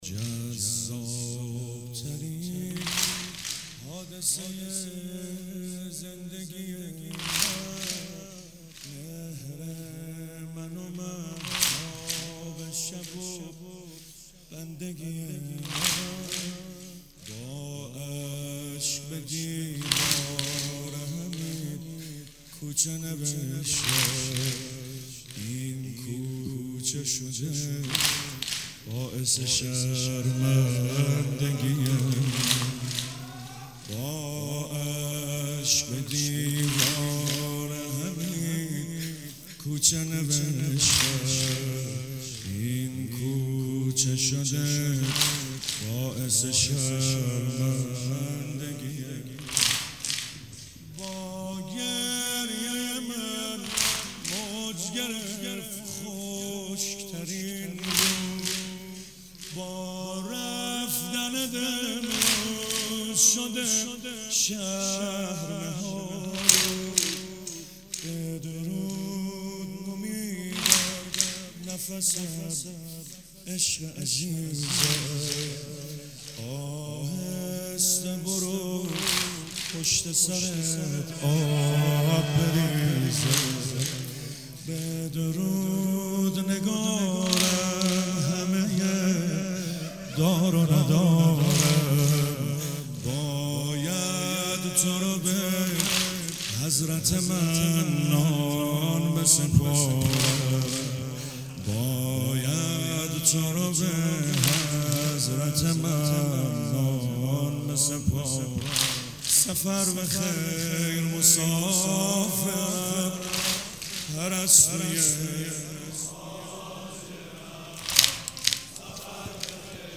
ایام فاطمیه 97 - یزد - واحد - جذابترین حادثه زندگی من